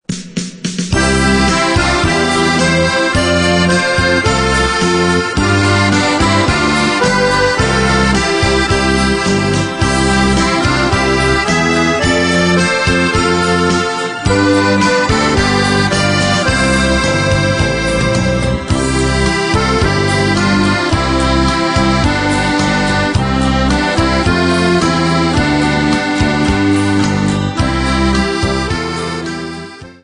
Besetzung: Akkordeon mit CD